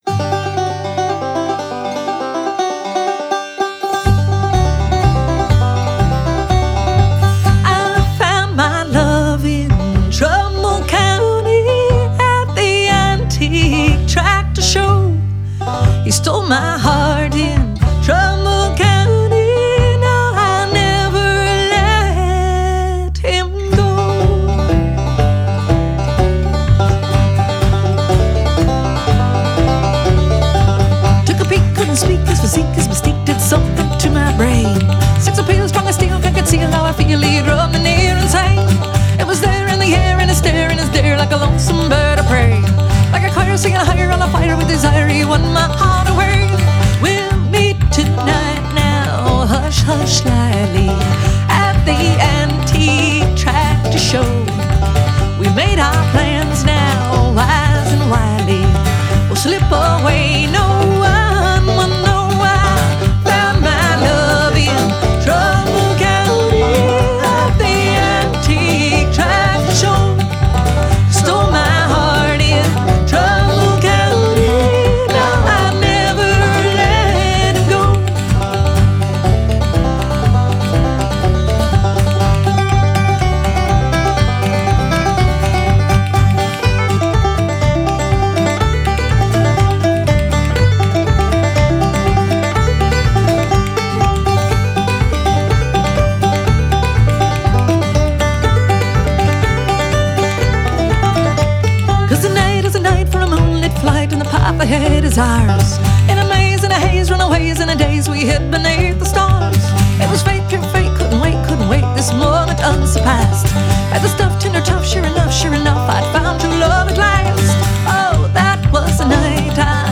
Genre: Alternative Folk, Singer/Songwriter, Country